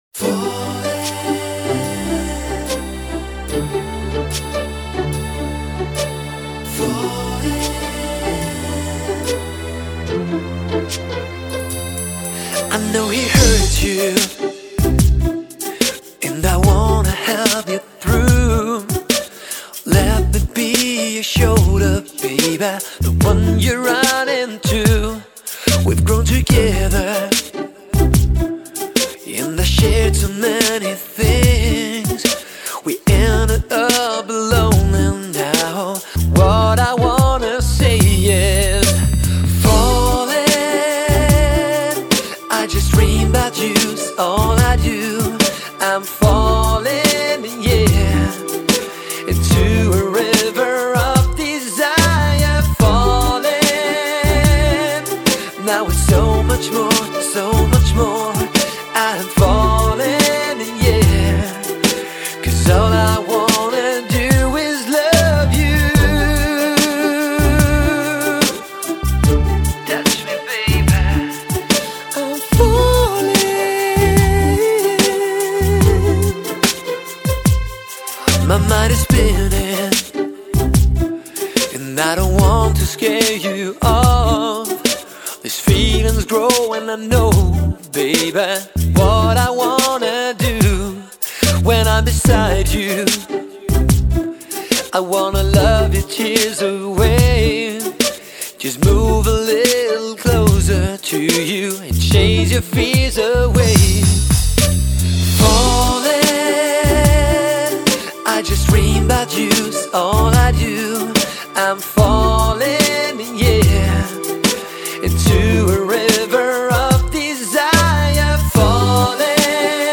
Mid Tempo Ml Vox, keys, drums, bass